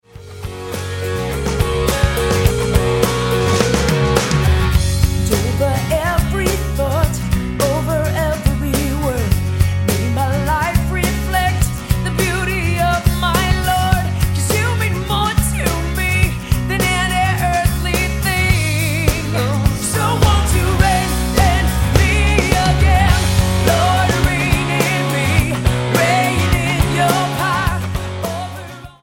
STYLE: Rock
Recorded live at Oklahoma Baptist University